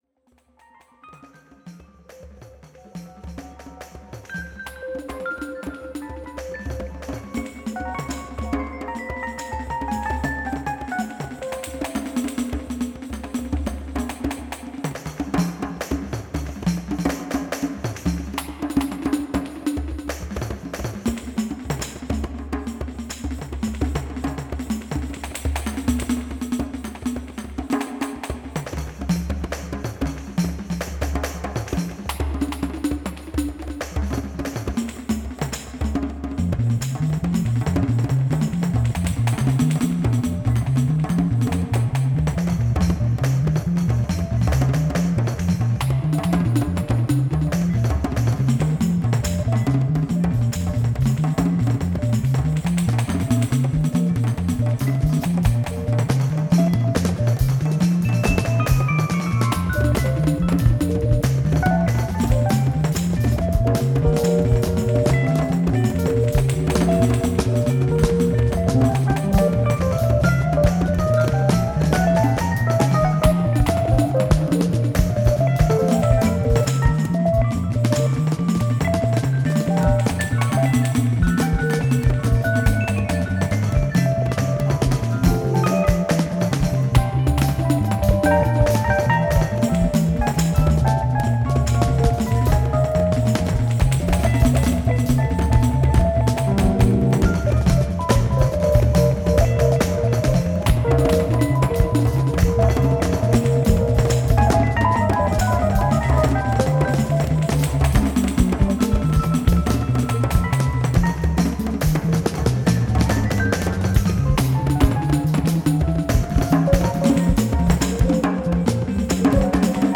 contributing percussion and voice